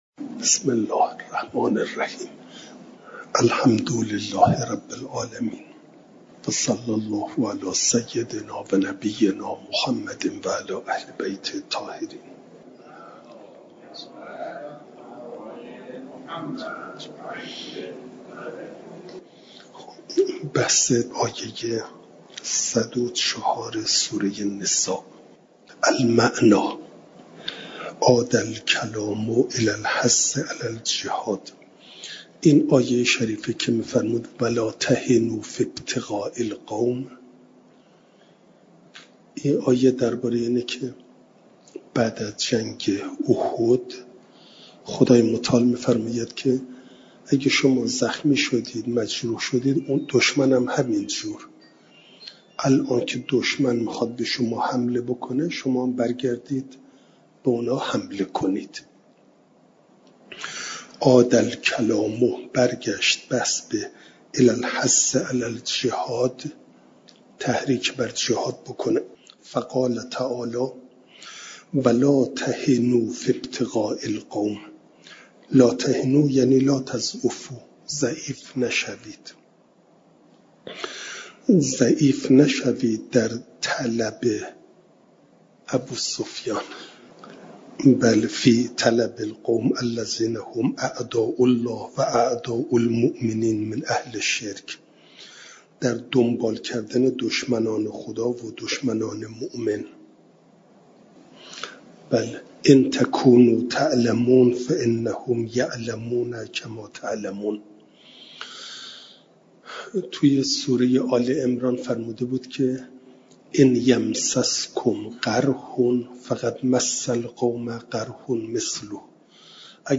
جلسه سیصد و هشتاد و هشتم درس تفسیر مجمع البیان